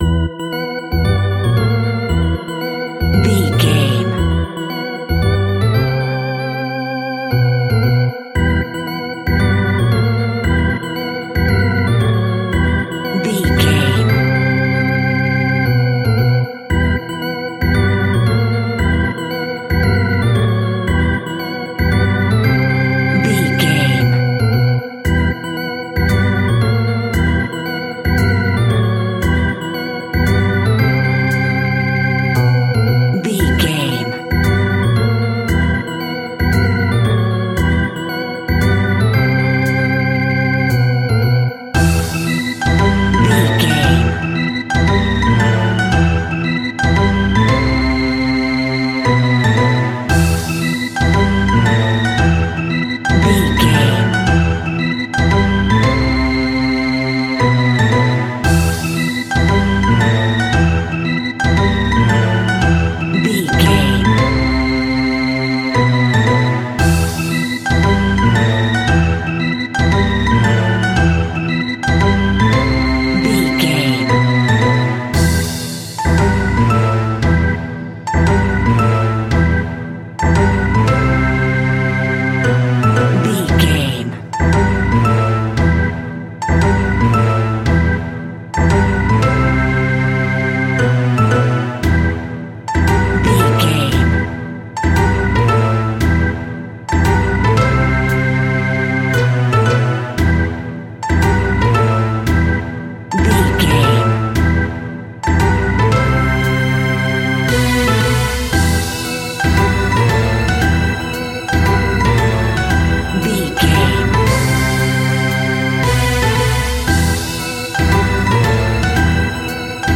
Diminished
tension
ominous
dark
suspense
haunting
eerie
electric organ
synthesiser
drums
percussion
strings
harp
horror
creepy
spooky